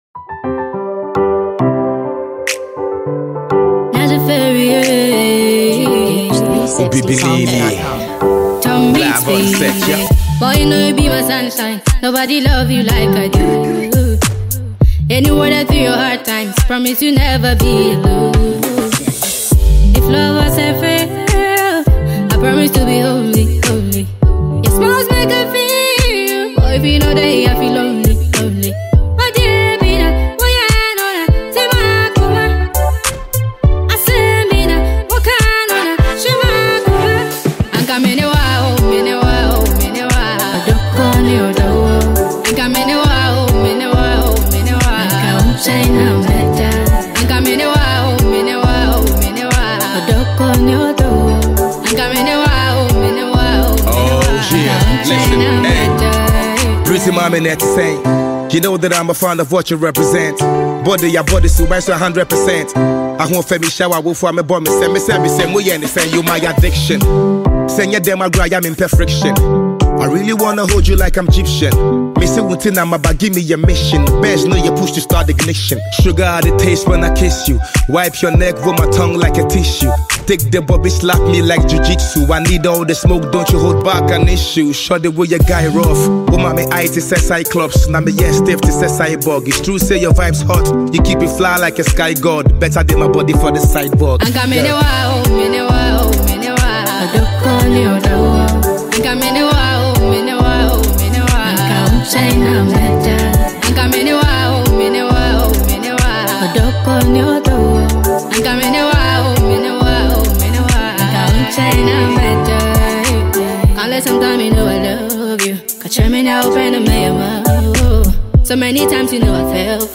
Ghana Music
Ghanaian talented songstress
features rapper